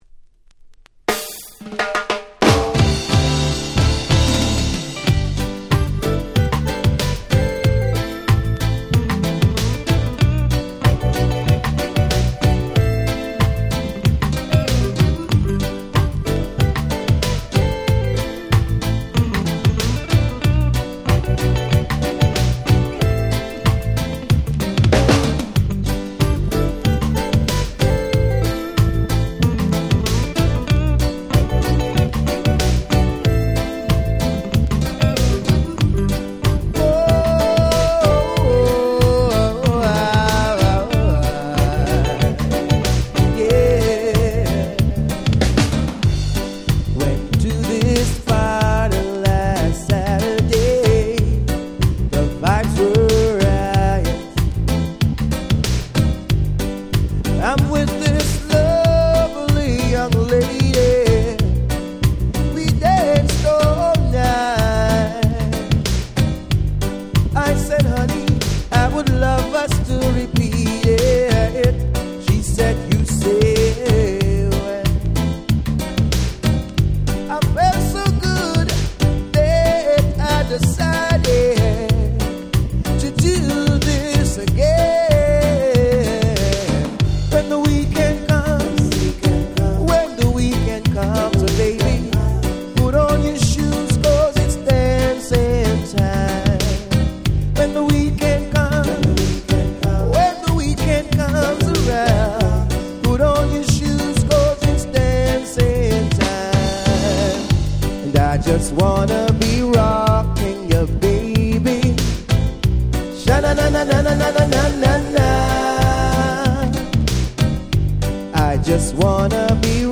94' Very Nice Reggae / R&B !!
詳細不明ながら日本企画のナイスな歌モノレゲエ！！
両面共にGroovyで最高！！
90's lovers ラバーズレゲエ